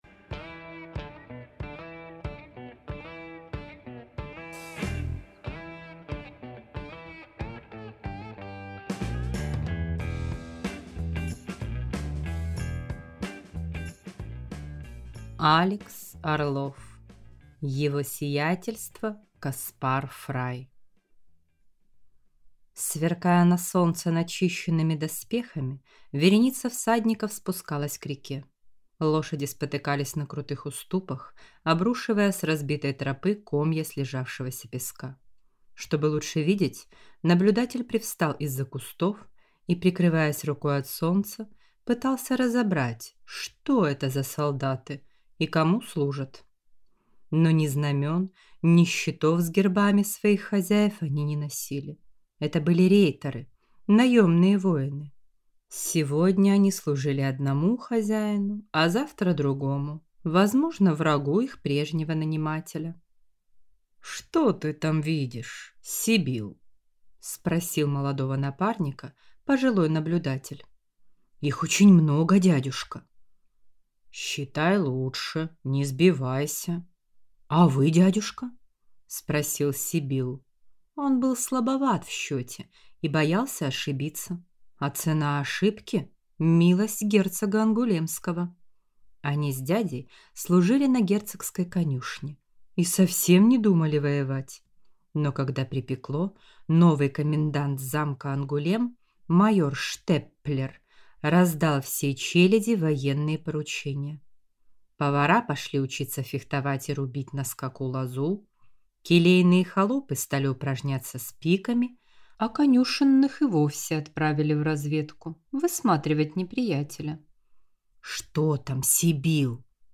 Аудиокнига Его сиятельство Каспар Фрай | Библиотека аудиокниг